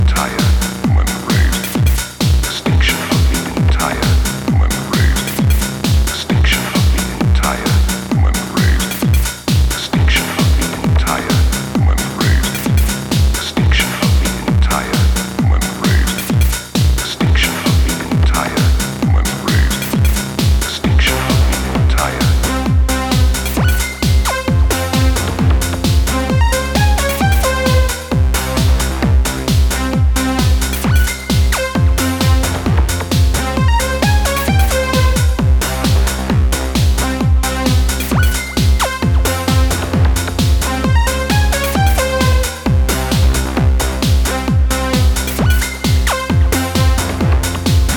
with the signature focus on kick drum and bass line